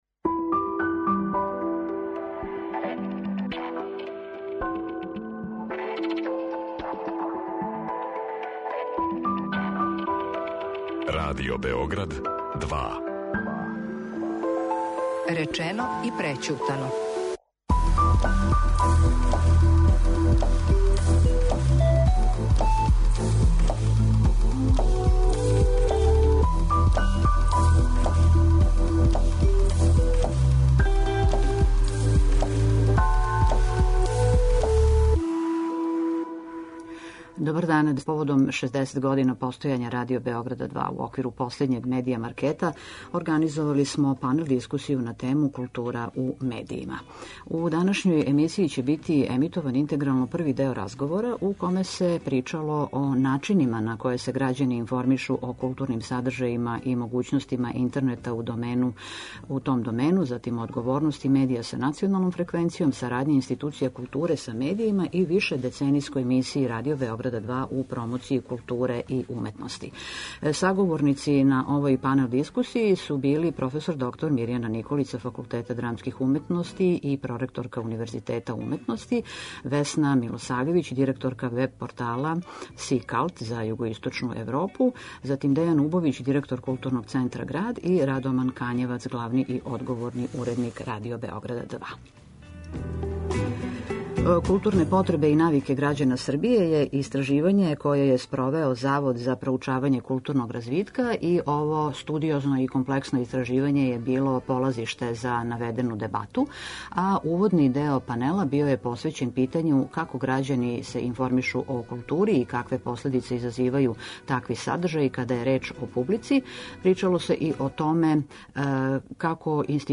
Поводом 60 година постојања Радио Београд 2 је у оквиру последњег Медија маркета, организовао панел дискусију на тему "Култура у медијима".